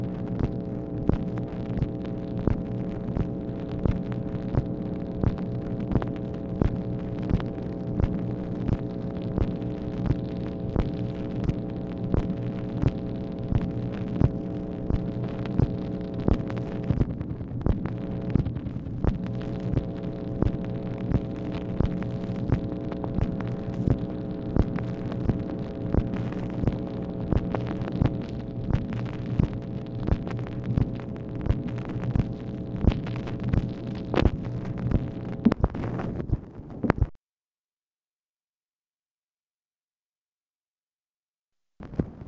MACHINE NOISE, April 30, 1965